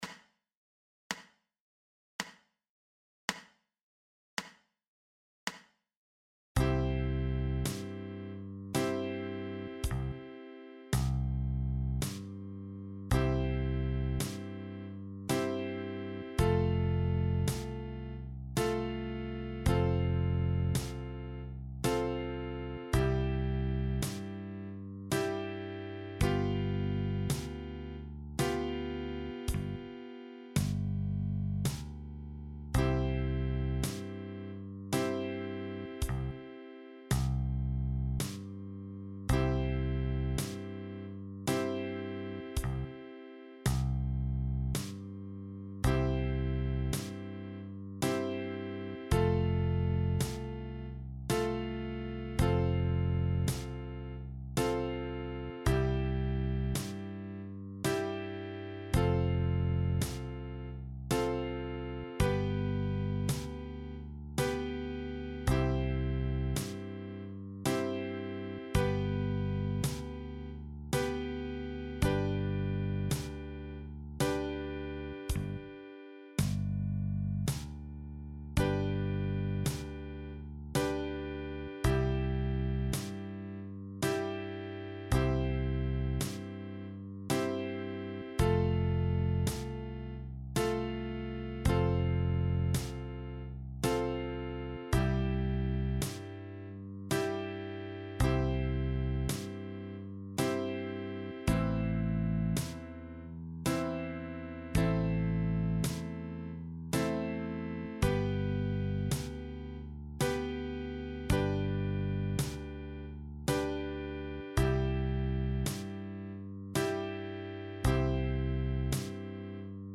Tremolo Mundharmonika Traditional
Der Song ist in einem langsamen Tempo aufgenommen, im zweiten Sound ist nur die Begleitung zu hören - "Playalong". Die Instrumentierung wurde so gewählt dass sich der Klang möglichst wenig mit dem des eigenen Instrumentes vermischt.